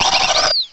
sovereignx/sound/direct_sound_samples/cries/mothim.aif at master